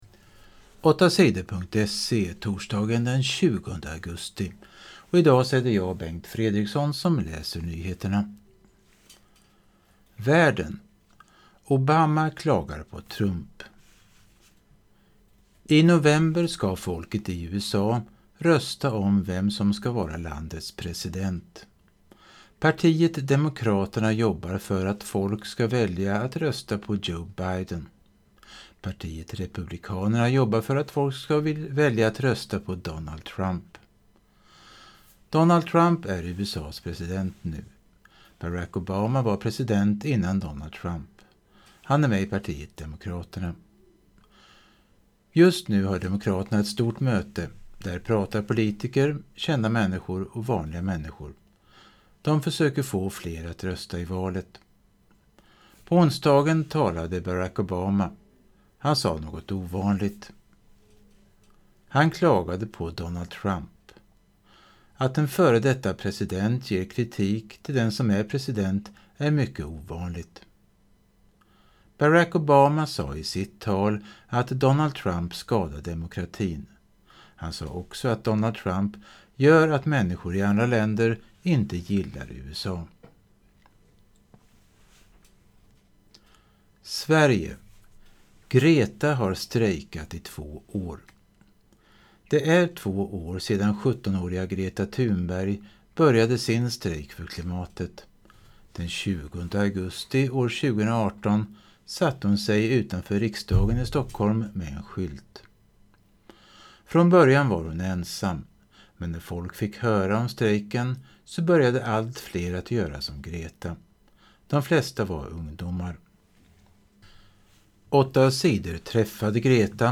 Nyheter på lätt svenska den 20 augusti